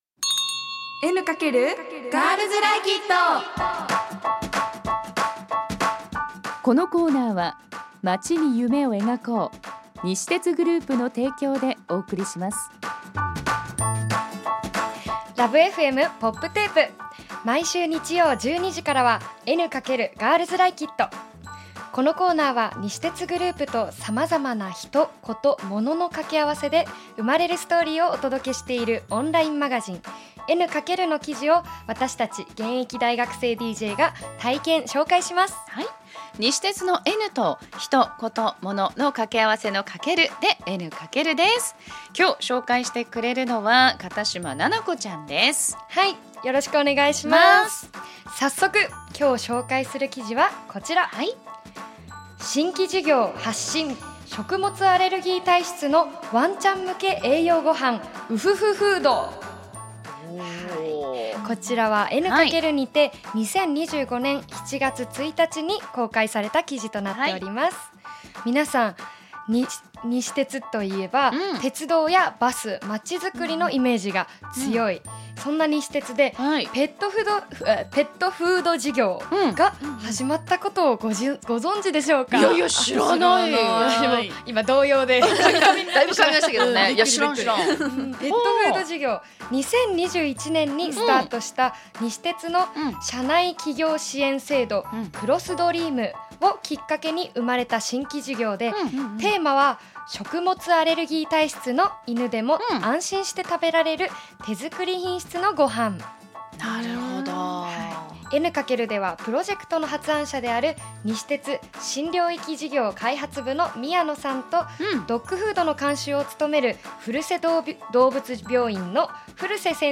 女子大生DJが「N× エヌカケル」から気になる話題をピックアップ！